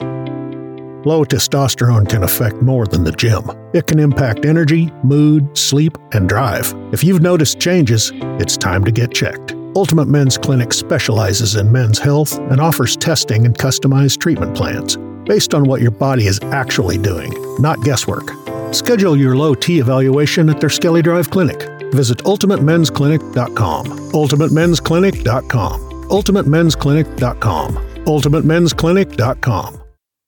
Male
A Recent Radio Commercial
Words that describe my voice are Storyteller, Conversational, Relatable.